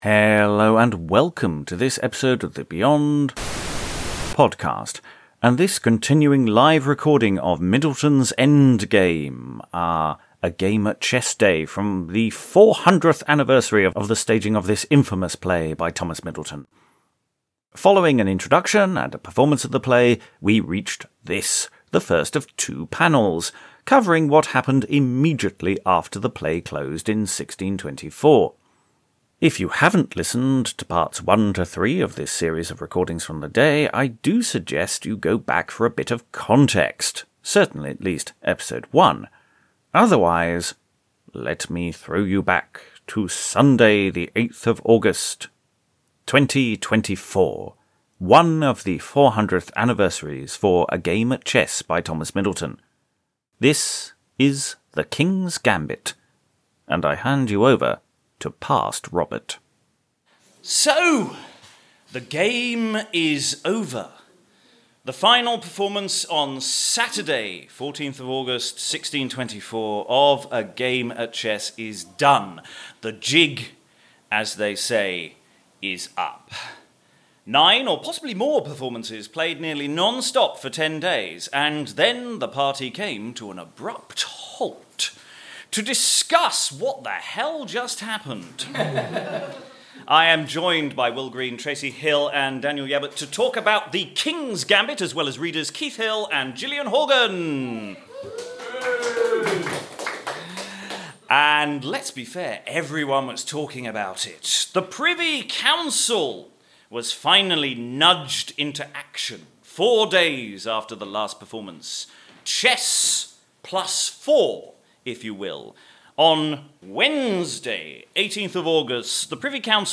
The whole event, as ever, was recorded, and we're releasing these over the next few months. This is episode 4, which covers what happened after the play closed, with readings of letters from the time, official records, and discussion with our expert panel.